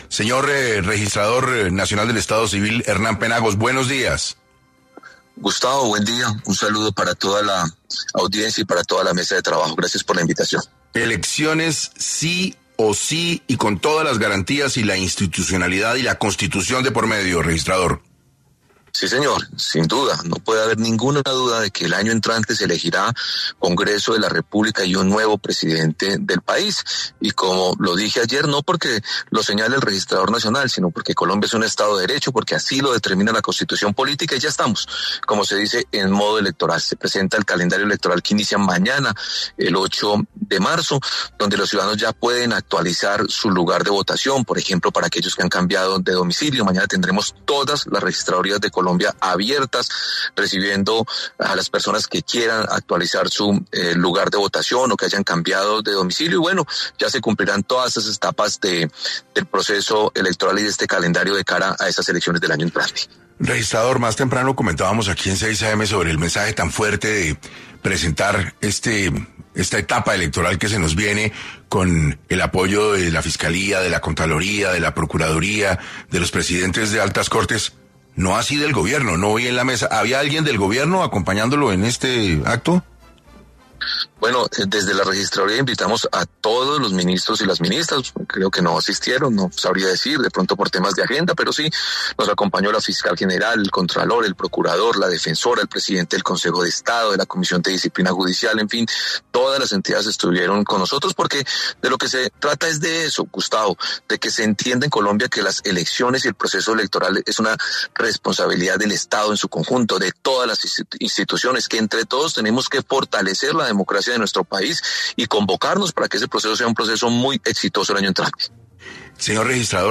Hoy en entrevista para 6AM, el Registrador Nacional del Estado Civil, Hernán Penagos, con el fin de dar tranquilidad al pueblo colombiano, aclaró y explicó cómo se llevarán a cabo y los mecanismos que lucharán con los actos antidemocráticos que se puedan presentar.